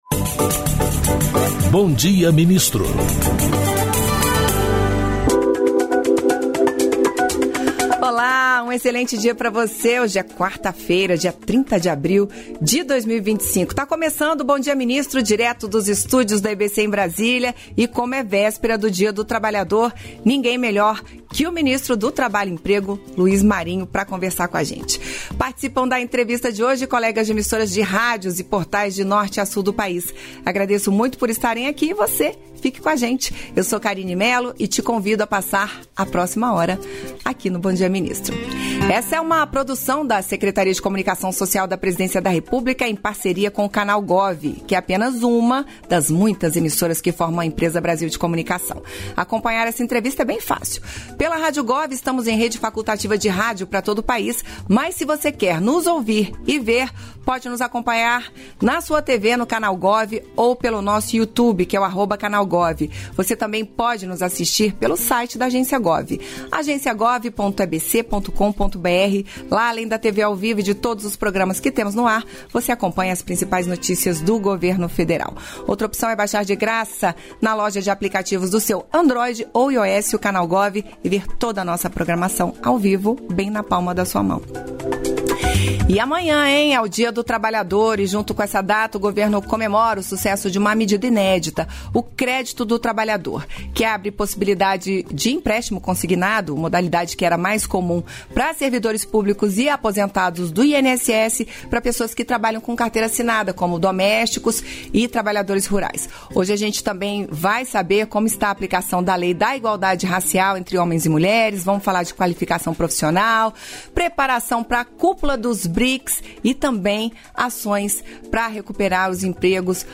Íntegra da participação ministro do Trabalho e Emprego, Luiz Marinho, no programa "Bom Dia, Ministro" desta quarta-feira (30), nos estúdios da EBC em Brasília (DF).